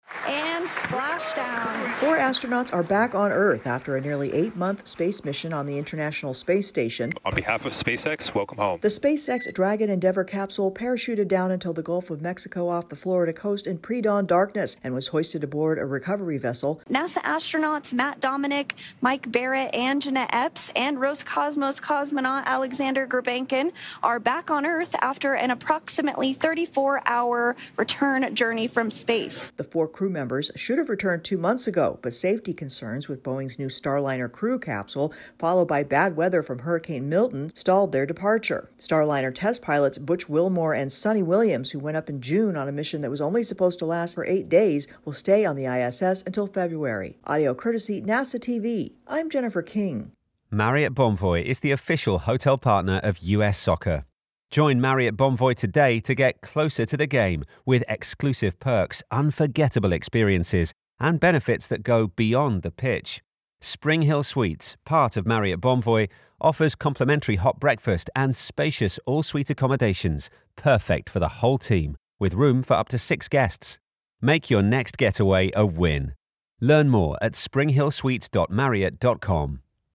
A SpaceX craft brings back four astronauts after an extended mission. AP correspondent